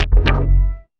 バーン